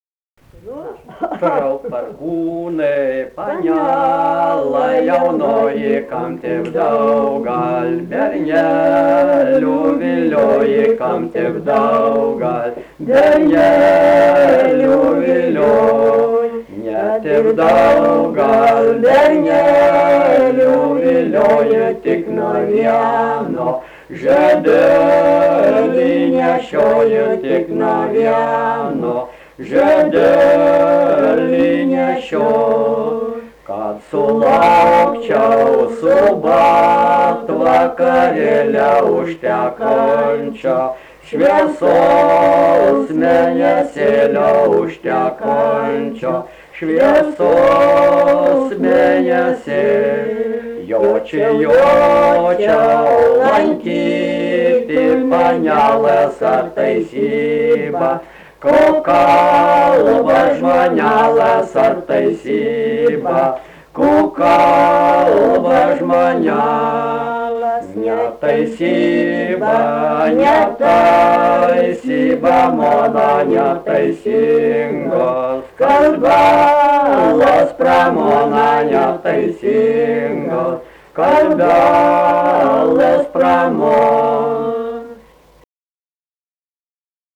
Biržai
instrumentinis
skudučiai